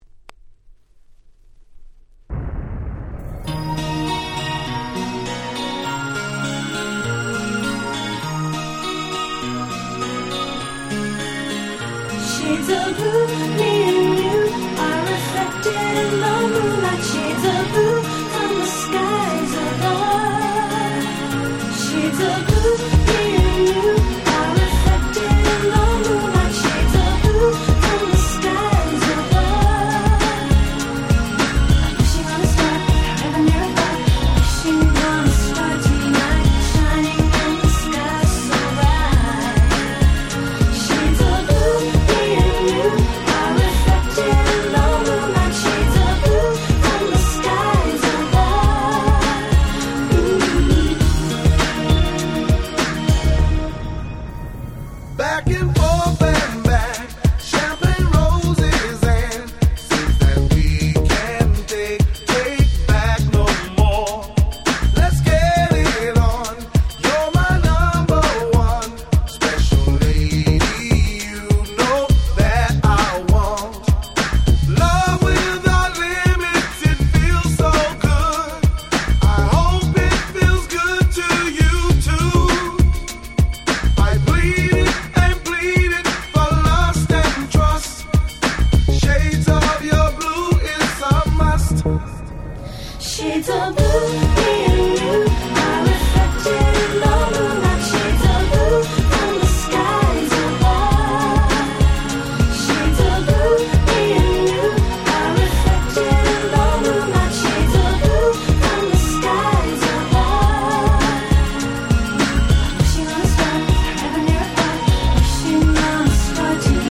97' Nice Euro G-Rap !!
でもサビでは女性Vocalが入って来たりで持ち前のキャッチーさは健在。
フレッシュアンドファンキー 90's Euro-G キャッチー系 R&B